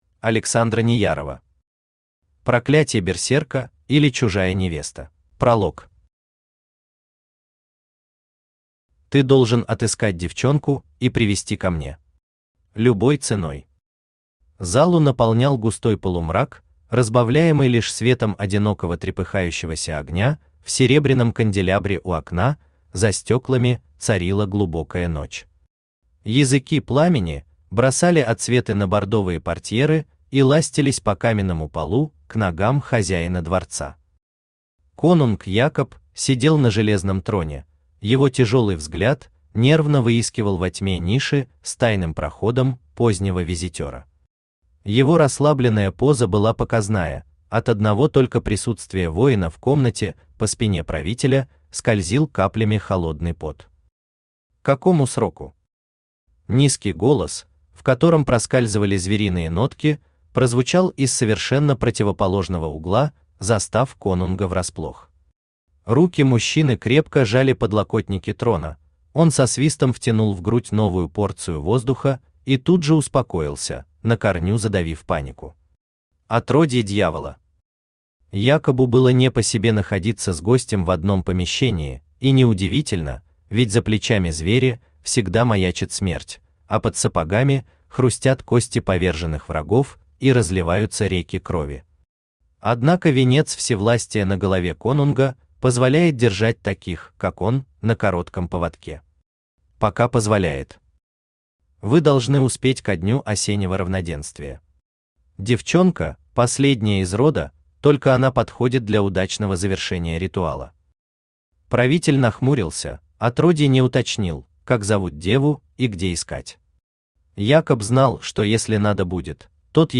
Аудиокнига Проклятье берсерка, или Чужая невеста | Библиотека аудиокниг
Aудиокнига Проклятье берсерка, или Чужая невеста Автор Александра Неярова Читает аудиокнигу Авточтец ЛитРес.